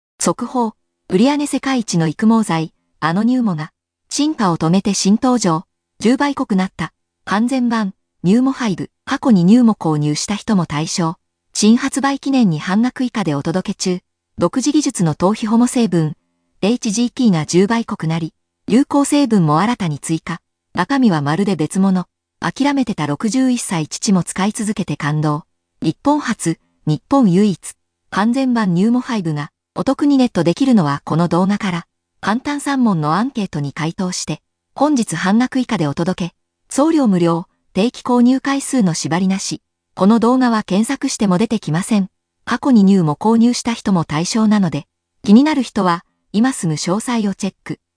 I just wanted you to listen to its remarkably badly-dubbed narrations.
The audio quality and the accent of the female voice are not bad, but in terms of intonation, it’s hilariously off. This verbal caricaturization is something only an AI- voice generator can achieve, but a real human can’t. The noticeable part starts at around 9 seconds.